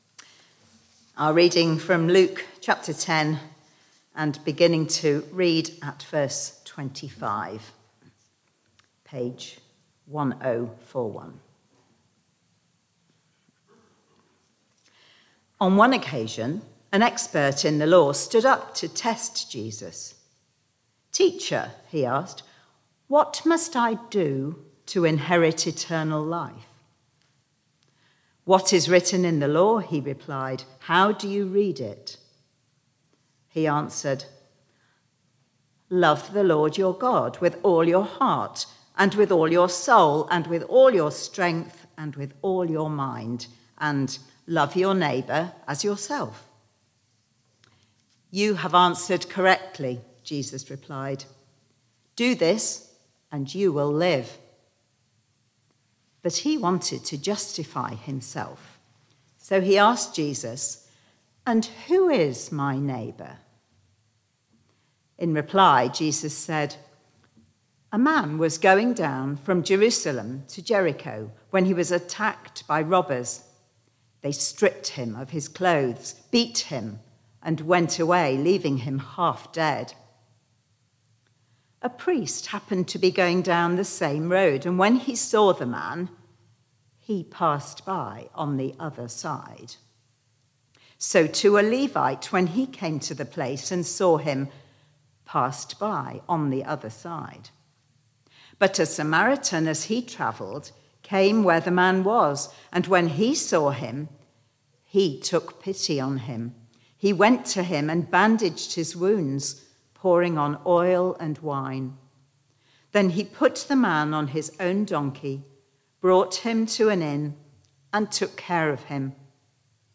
Barkham Morning Service
Theme: The Good Samaritan Reading and Sermon